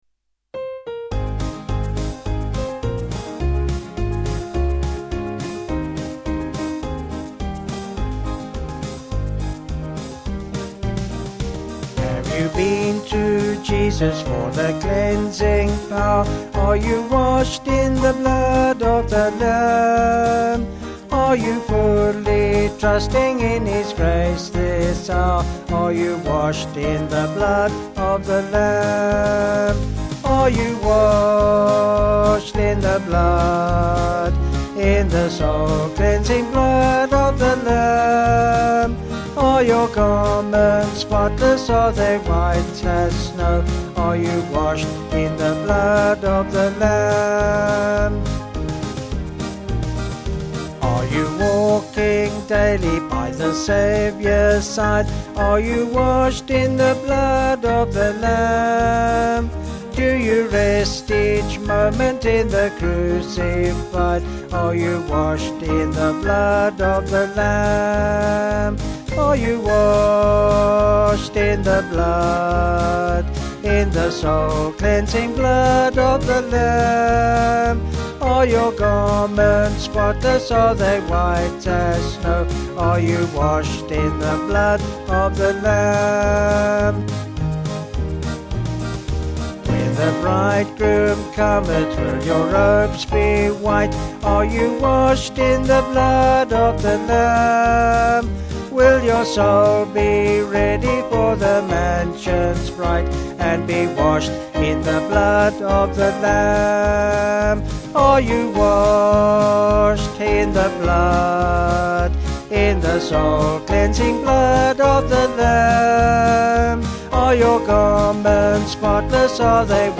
Vocals and Organ   264.2kb Sung Lyrics 2.6mb